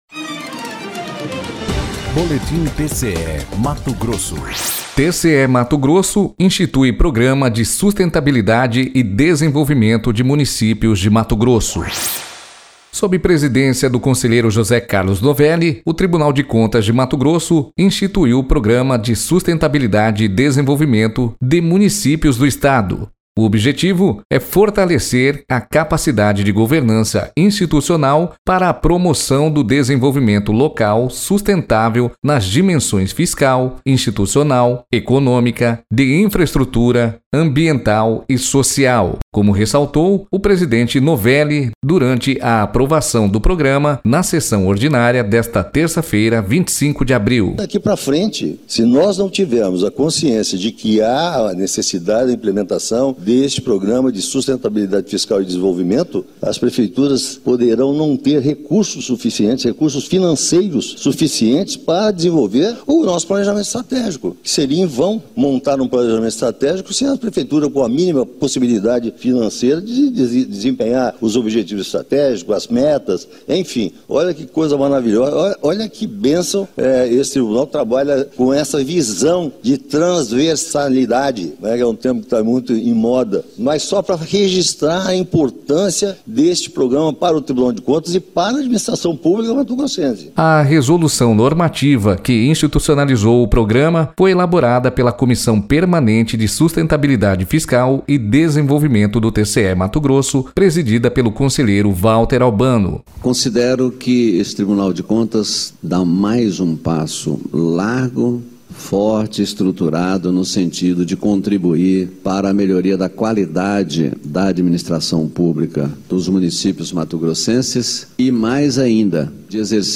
Sonora: José Carlos Novelli – conselheiro presidente do TCE-MT
Sonora: Valter Albano – conselheiro do TCE-MT
Sonora: Antonio Joaquim – conselheiro do TCE-MT
Sonora: Sérgio Ricardo – conselheiro do TCE-MT